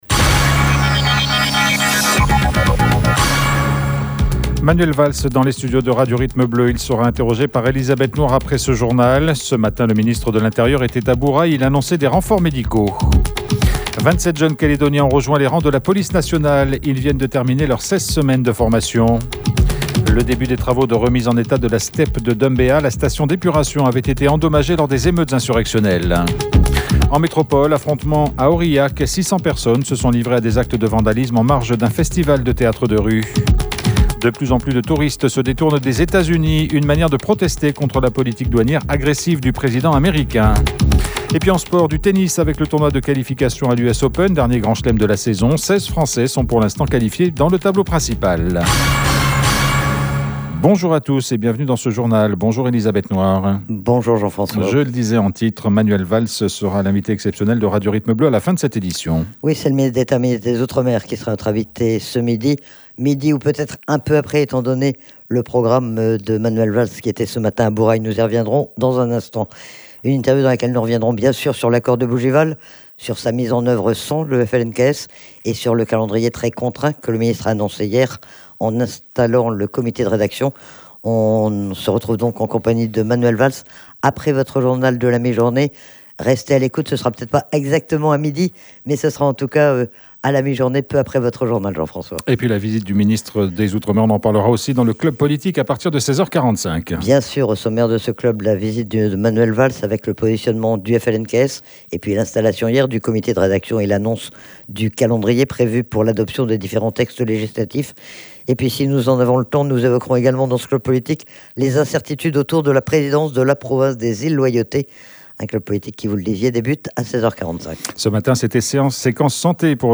Manual Valls était l’invité exceptionnel, du magazine Transparence